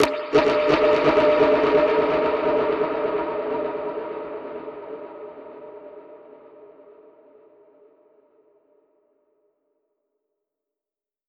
Index of /musicradar/dub-percussion-samples/85bpm
DPFX_PercHit_D_85-01.wav